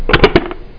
clicks
1 channel